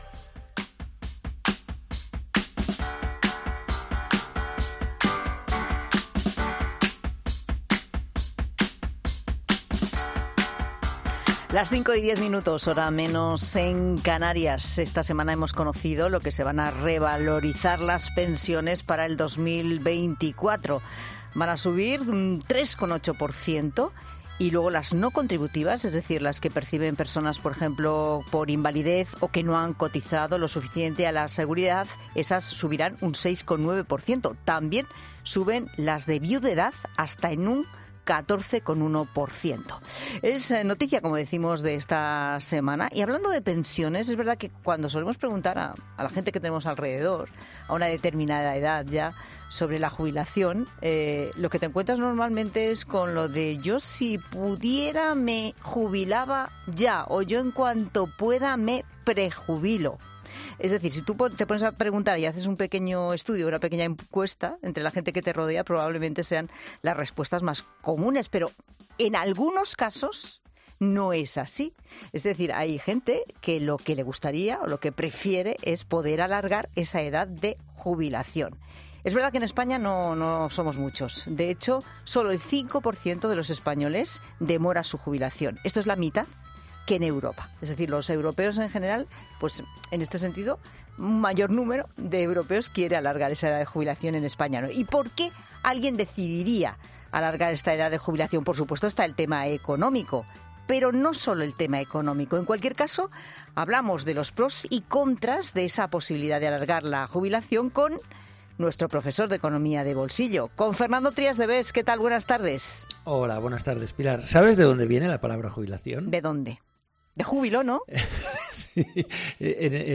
El economista, Fernando Trías de Bes, ha explicado en 'La Tarde' algunos de los motivos por los que la gente suele alargar el periodo de jubilación